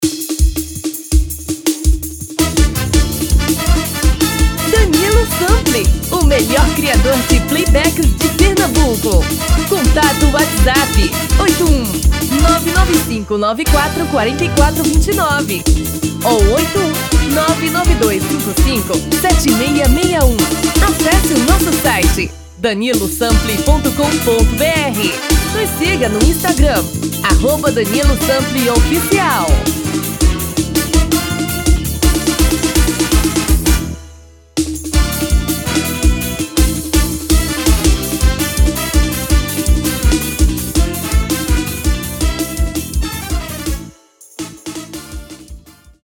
TOM ORIGINAL